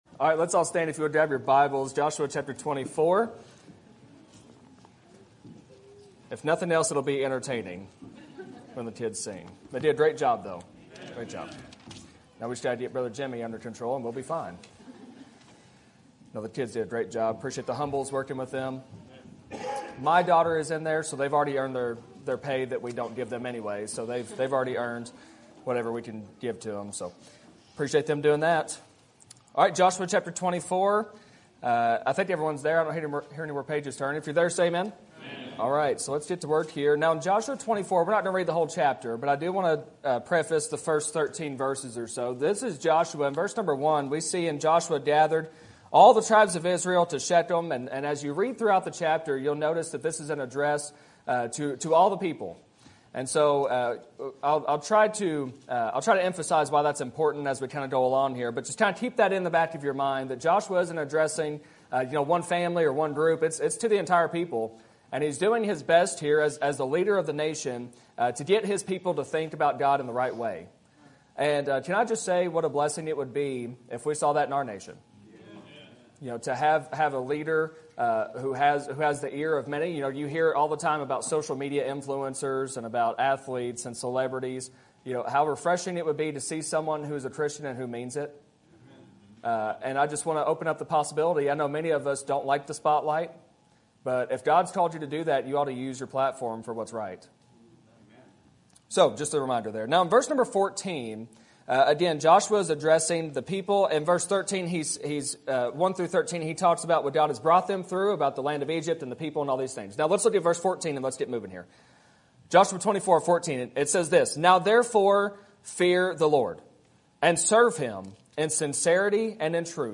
Sermon Topic: General Sermon Type: Service Sermon Audio: Sermon download: Download (23.5 MB) Sermon Tags: Joshua Call Serve Commitment